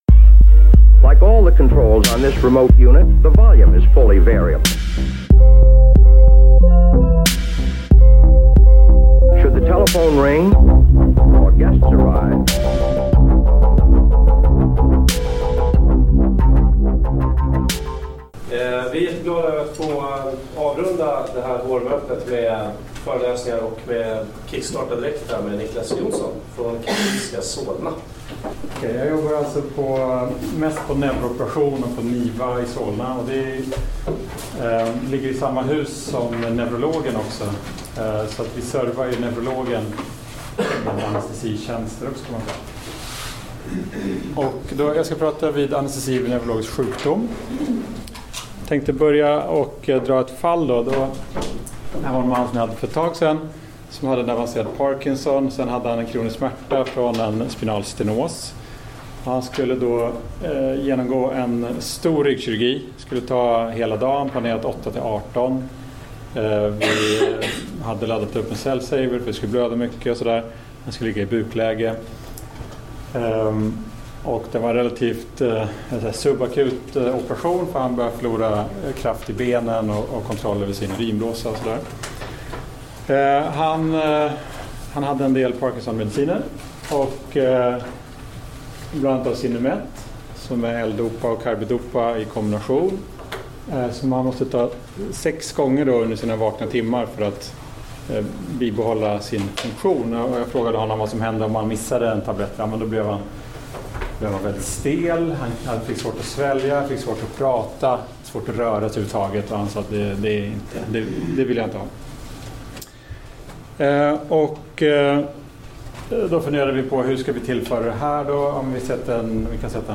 Föreläsningen hölls på SYA:s vårmöte 2017.